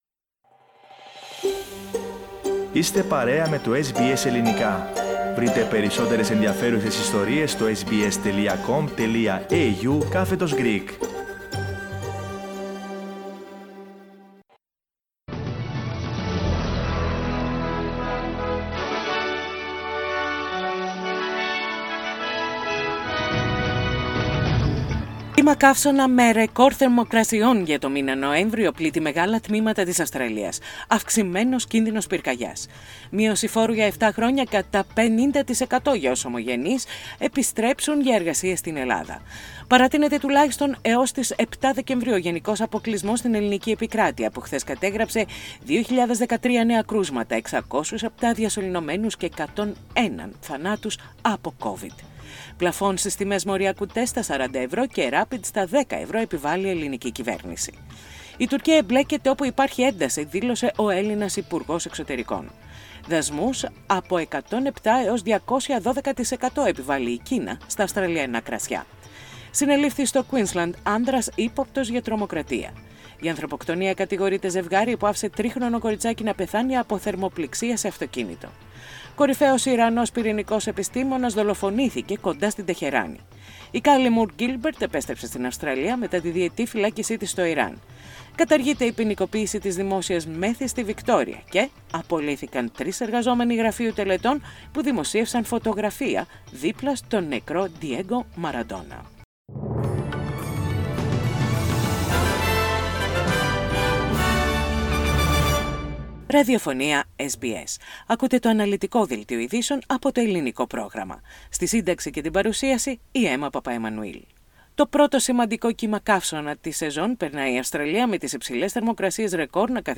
Δελτίο ειδήσεων - Σάββατο 28.11.20
Οι κυριότερες ειδήσεις της ημέρας από το Ελληνικό πρόγραμμα της ραδιοφωνίας SBS.